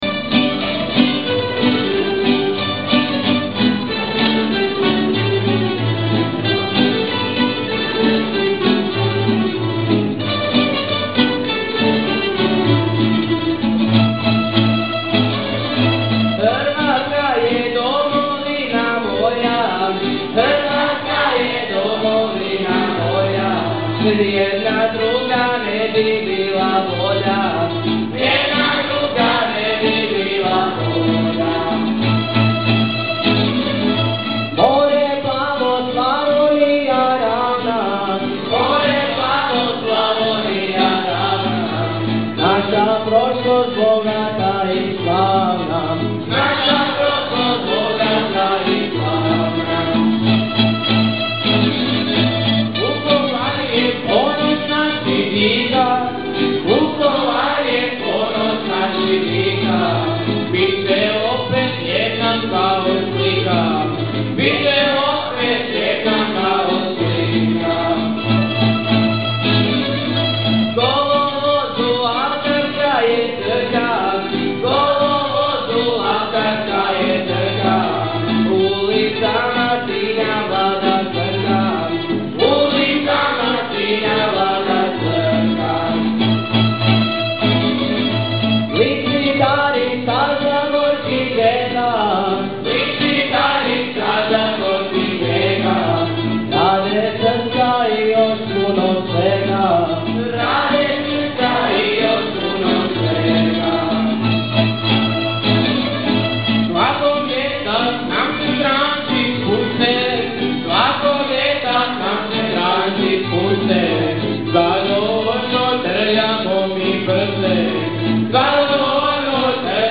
Dan je završio svečanom priredbom u kojoj su se izmjenjivale duhovite točke, a najveće ovacije izazvao je tamburaški orkestar škole izvedbom „Žabnjarskog bećarca“.
Sve aktivnosti dokumentirane su na web stranici škole, ondje se može provjeriti znanje u EU kvizu (pod linkovi) te poslušati bećarac.